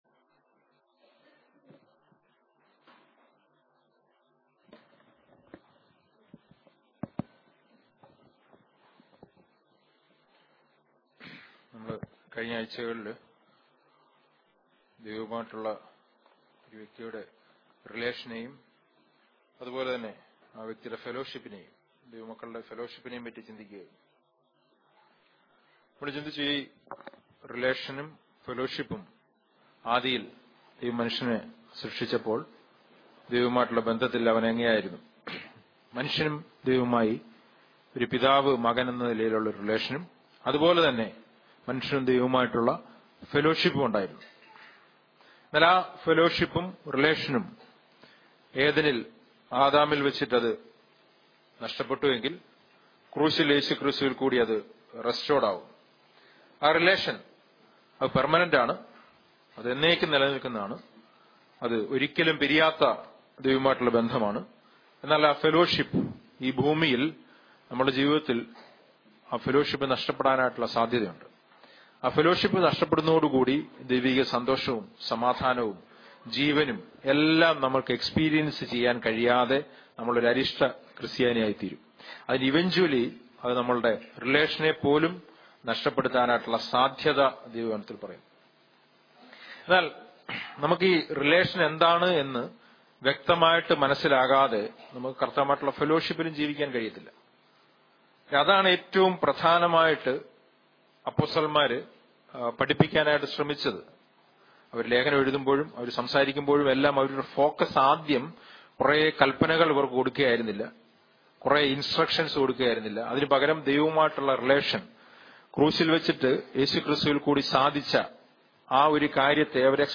SERMONS
Sermon